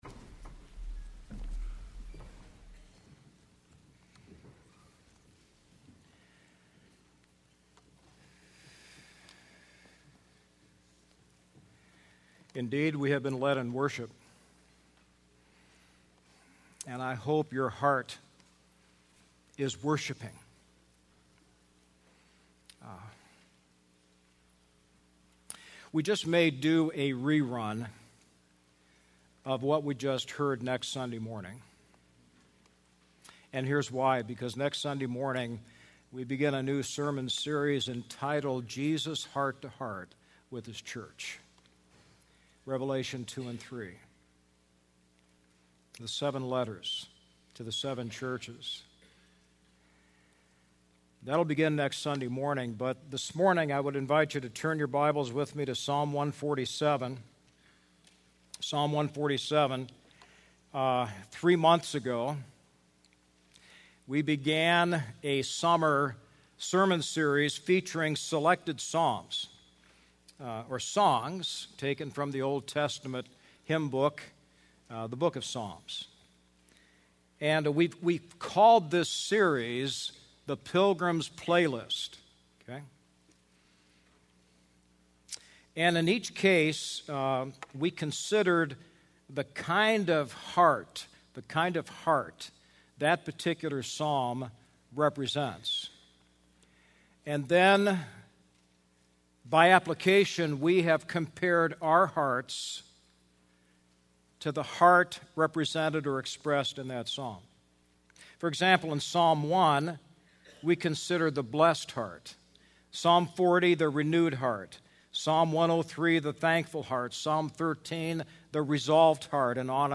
The Praising Heart | Baptist Church in Jamestown, Ohio, dedicated to a spirit of unity, prayer, and spiritual growth